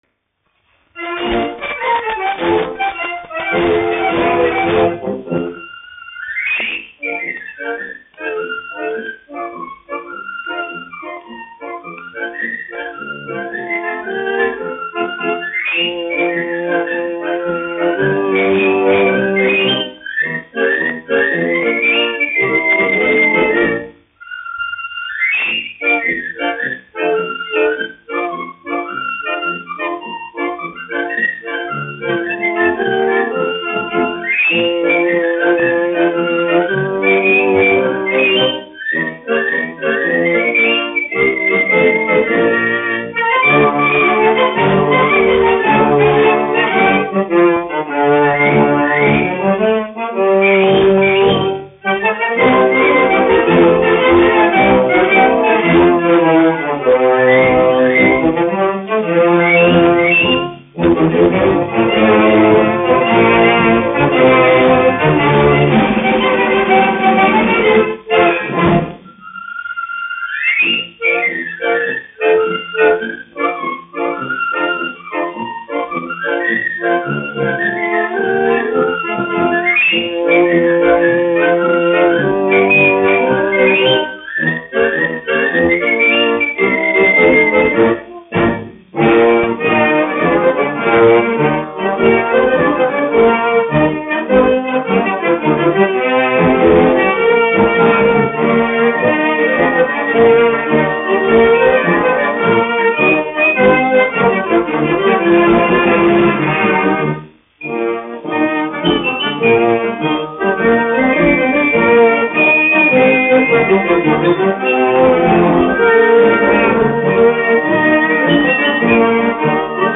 1 skpl. : analogs, 78 apgr/min, mono ; 25 cm
Marši
Populārā instrumentālā mūzika
Pūtēju orķestra mūzika
Skaņuplate